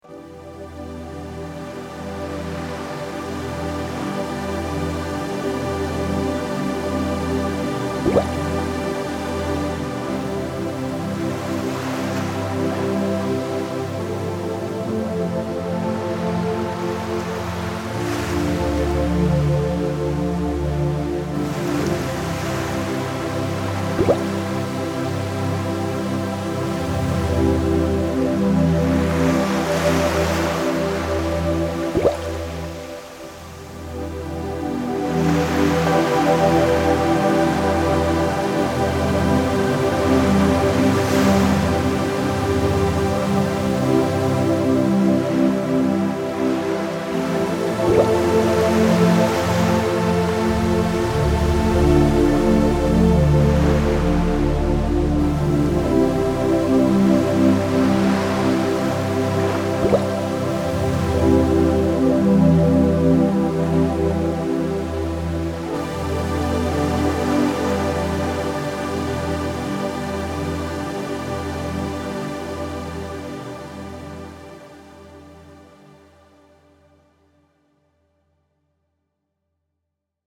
絵画作品の妨げにならないよう、神秘的かつ記憶に残りにくいメロディを制作しました。 また、波や泡の音を使用することで聴覚からも没入感が得られるようにしています。